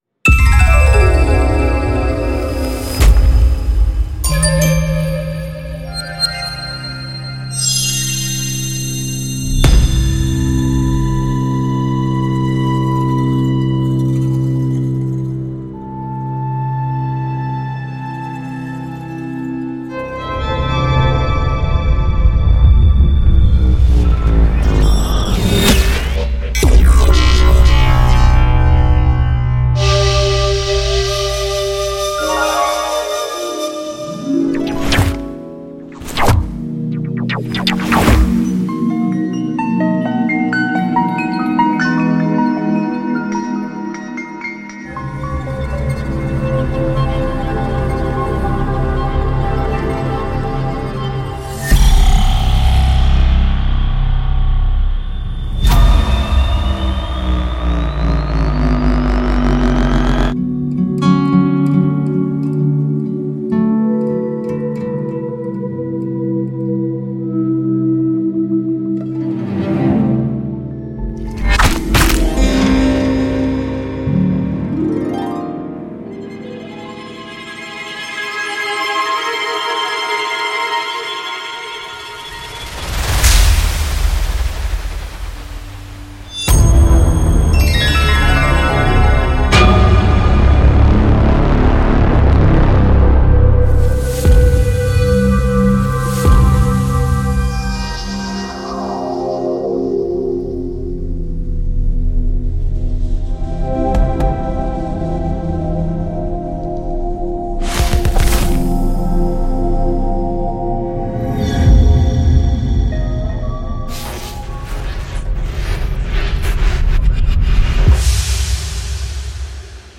پکیج افکت صوتی ترانزیشن لوگوی بازی و فیلم
Epic Stock Media’s Game & Film Logo Transitions collection brings producers and sound designers a carefully handcrafted, versatile and wide ranging catalog of the most useful designed motion graphic sound effects for audio branding, intros, outros, twitch, youtube, explainer videos, audio logos and title screens. Inside you’ll be able to utilize over 400 original audio assets that will help you make polished sonic branding sound types like movements, transitions, reveals, appears, disappears, cinematic’s, and accents especially useful for audiovisual needs and productions.
Epic.Stock.Media.Game.and.Film.Logo.Transitions.mp3